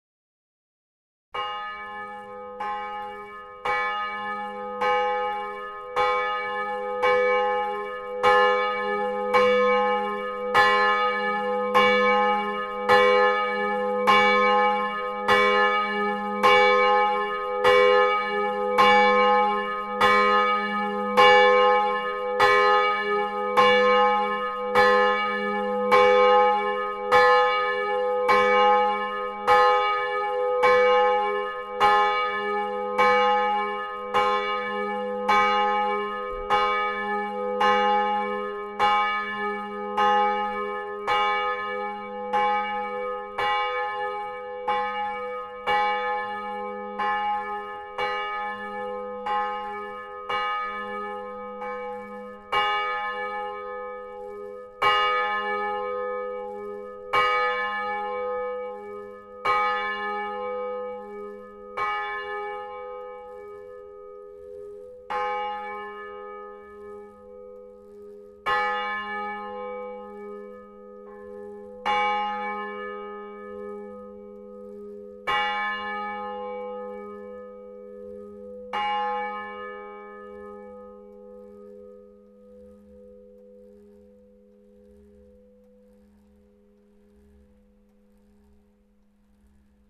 Les cloches d'Isières (3/3)
Vous pouvez écouter chacune des cloches présentes en clocher ci-dessous (volée manuelle) :
L'église d'Isières comporte trois cloches : une Drouot de 1817 et deux Michiels de 1953.